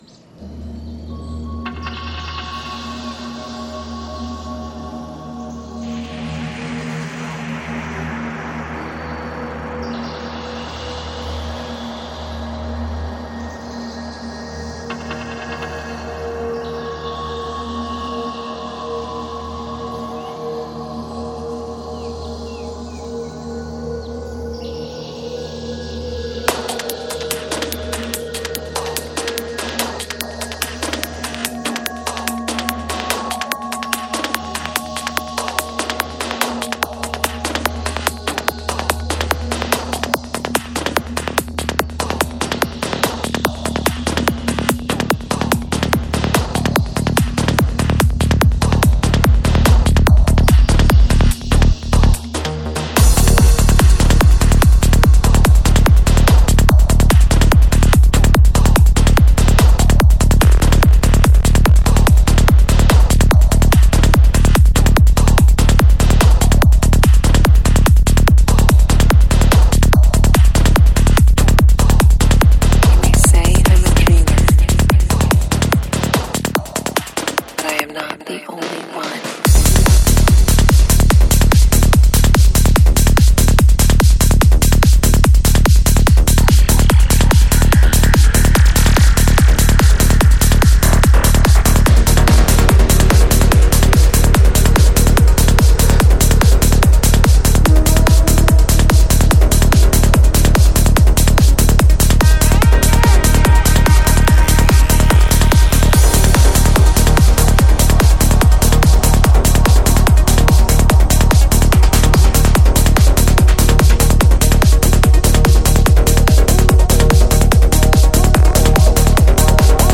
Жанр: Trance
Альбом: Psy-Trance